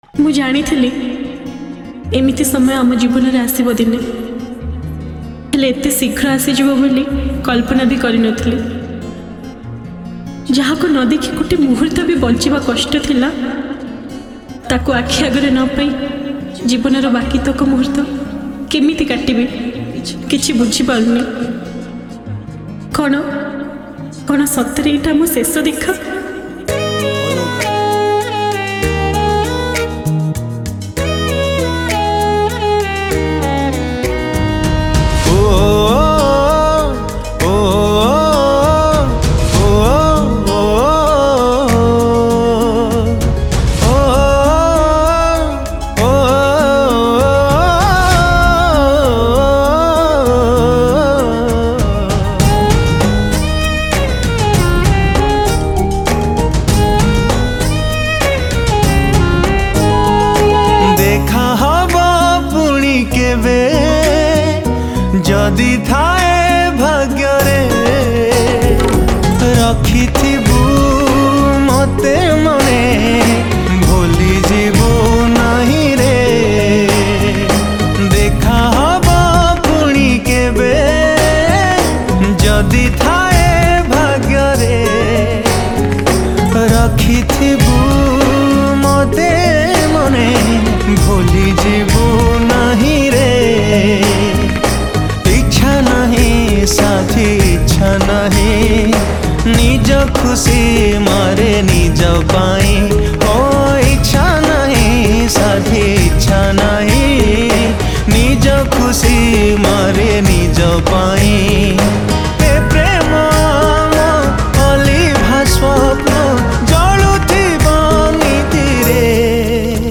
Song Type :Sad